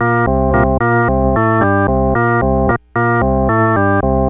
Track 15 - Organ.wav